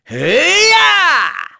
Neraxis-Vox_Casting1.wav